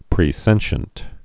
(prē-sĕnshənt, -shē-ənt)